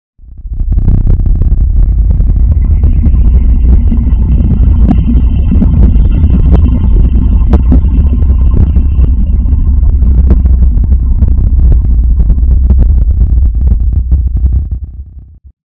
cave8.ogg